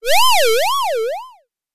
CartoonGamesSoundEffects